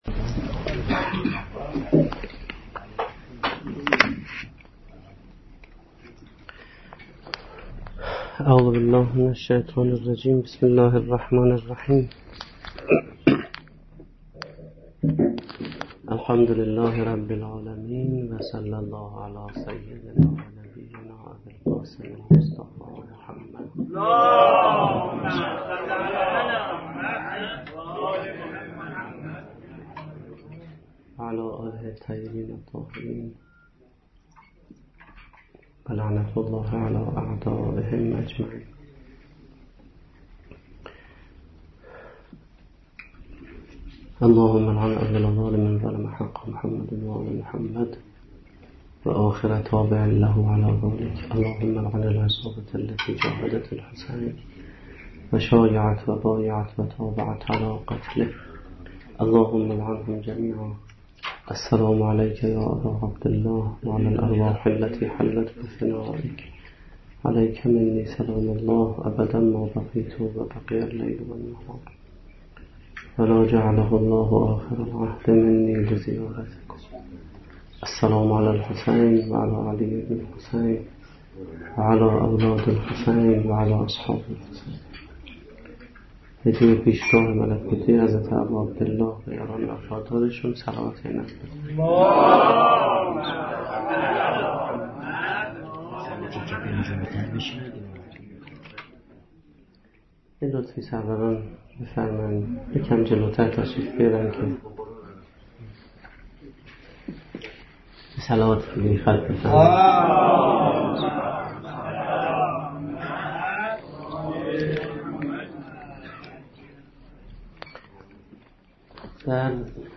سخنرانی
با موضوع ریشه های فرهنگی حادثه عاشورا - شب عاشورای محرم الحرام 1436